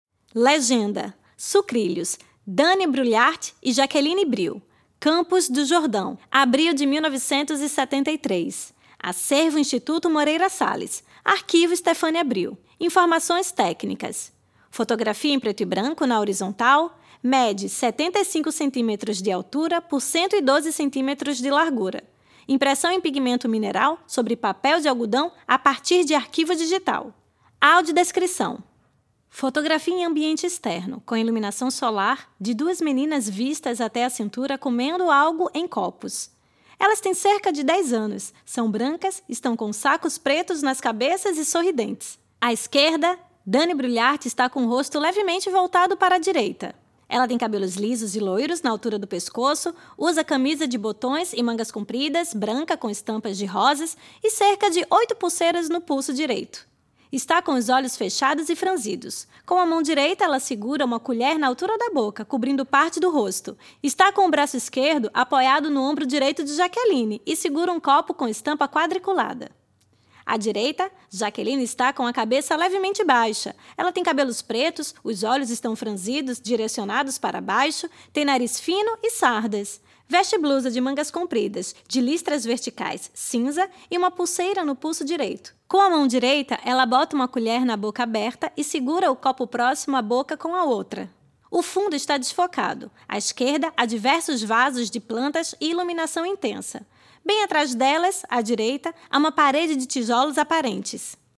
Faixa 1 - Audiodescrição